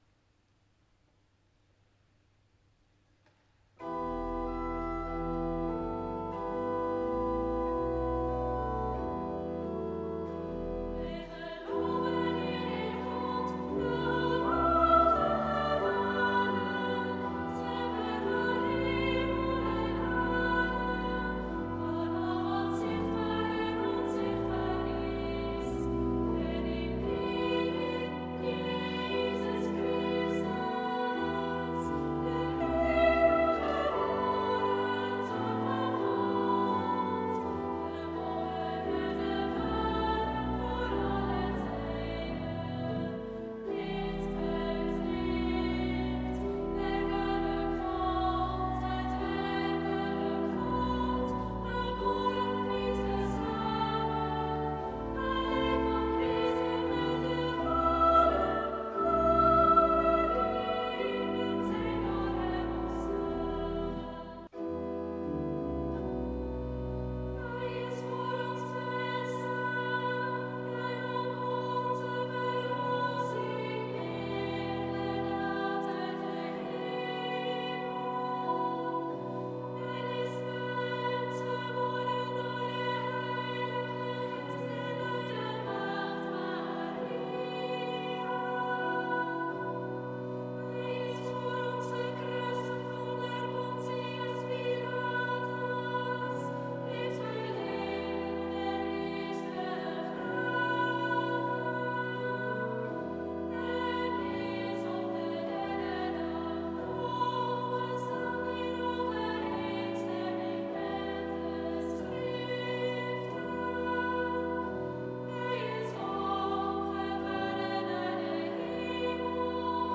Middenligging in d: zangpartij en begeleiding
Hoge ligging in e: zangpartij en begeleiding